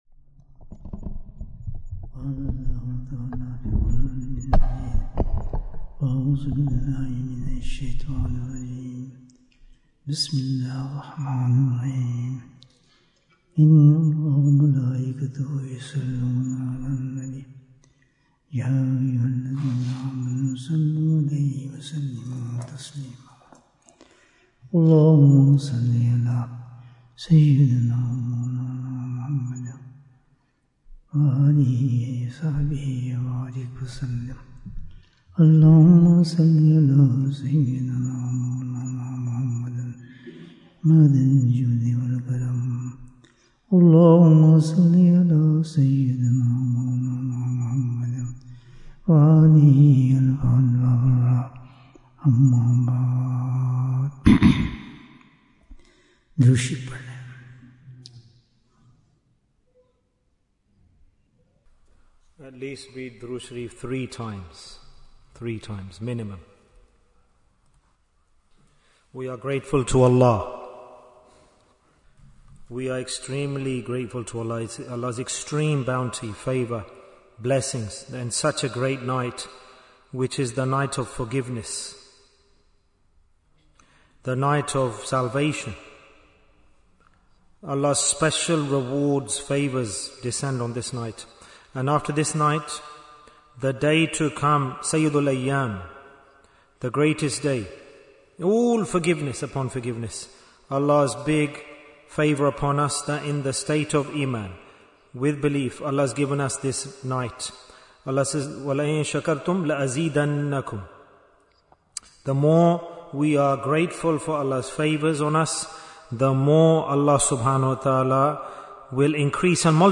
The Solution for Our Difficulties & Problems Bayan, 73 minutes4th December, 2025